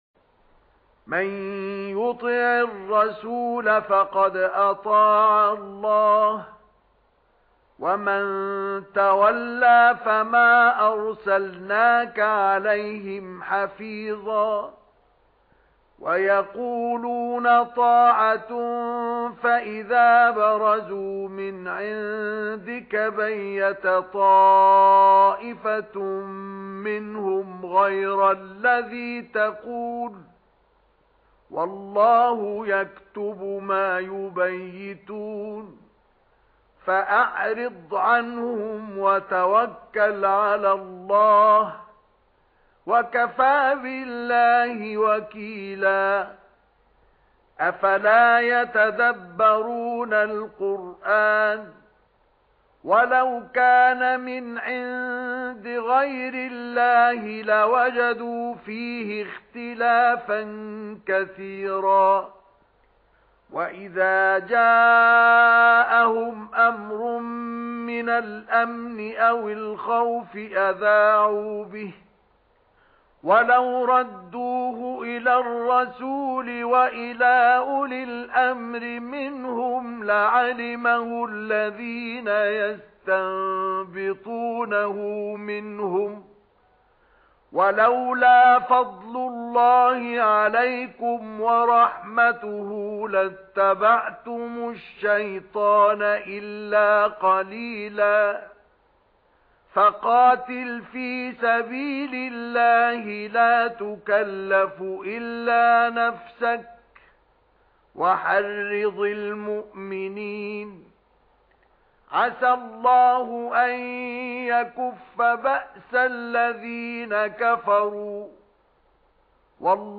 تلاوت ترتیل صفحه ۹۱ قرآن باصدای مصطفی اسماعیل+صوت و متن آیات
در ادامه تلاوت ترتیل آیات ۸۰ تا ۸۶ سوره مبارکه «نساء» واقع در صفحه ۹۱ قرآن کریم باصدای مرحوم مصطفی اسماعیل تقدیم می‌شود.